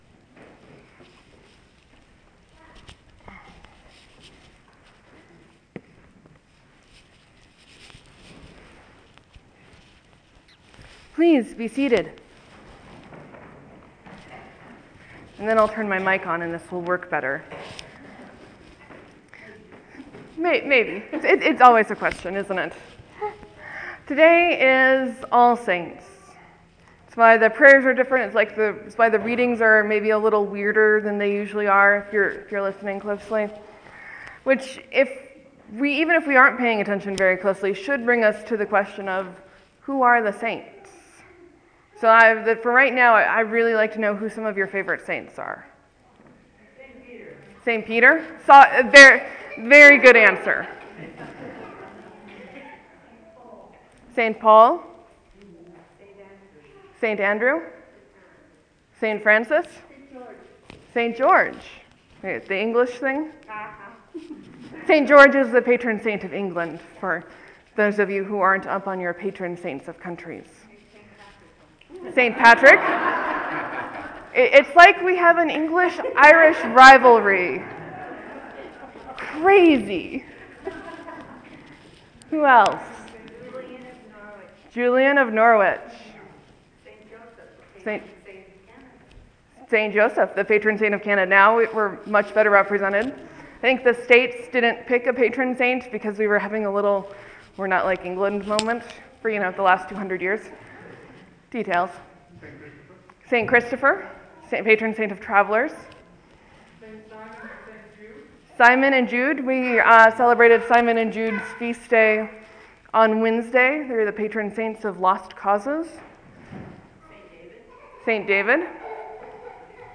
As happens, I preached on the theology of the day more than any particular lesson.